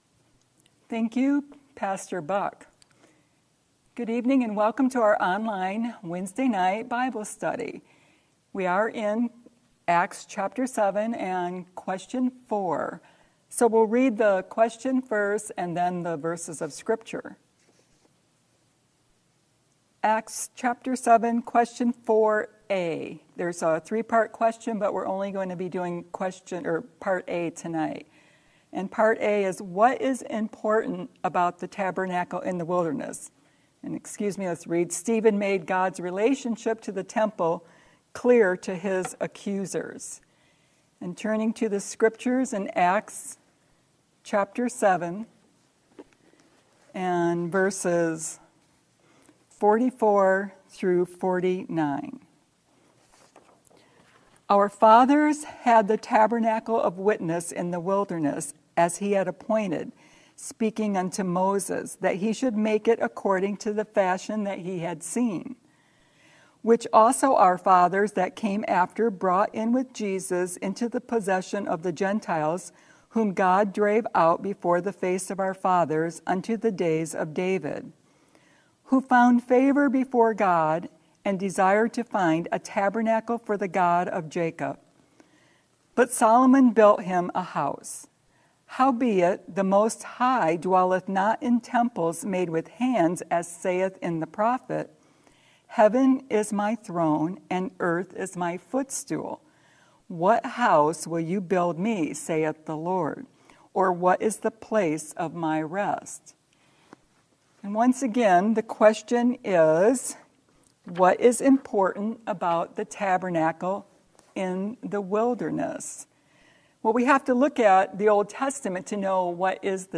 Acts Bible Study
Question for tonight’s teaching: